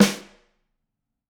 Index of /musicradar/Snares/Ludwig C
CYCdh_LudRimC-05.wav